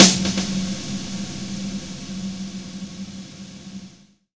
Big Drum Hit 11.wav